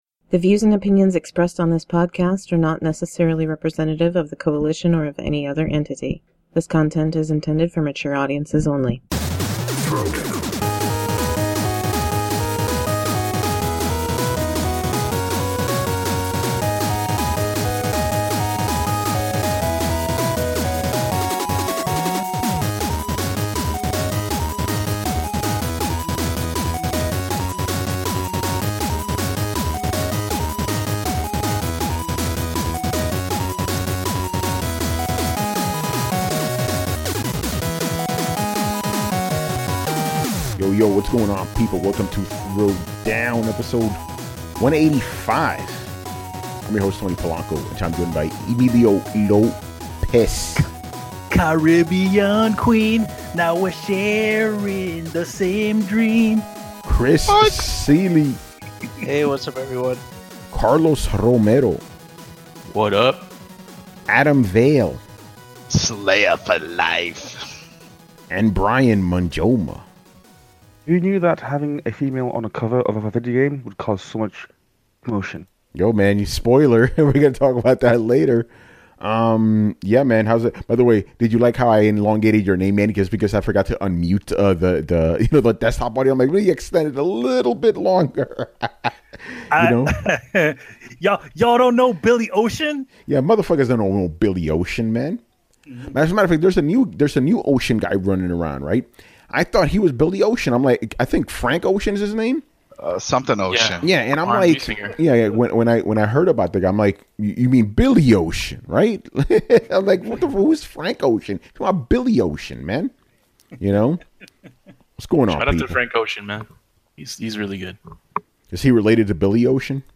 On this podcast/vidcast we discuss the latest video game news and topics in an uncompromising and honest manner.